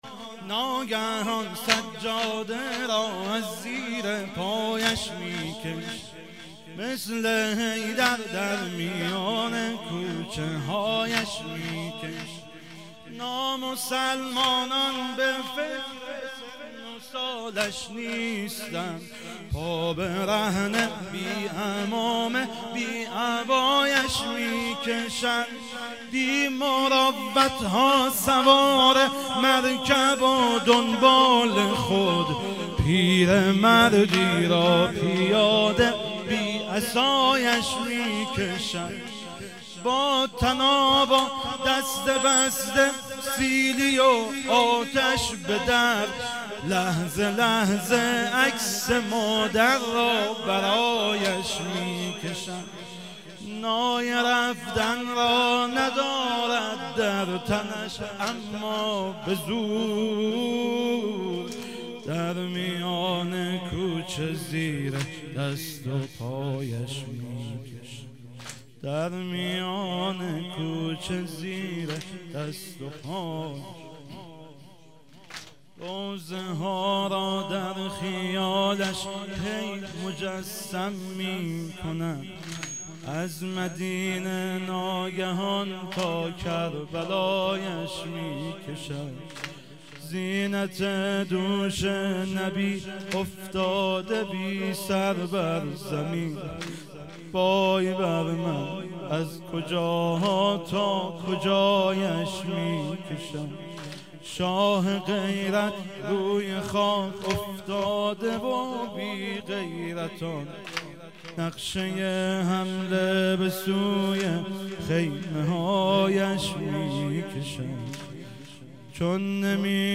شهادت امام صادق 1397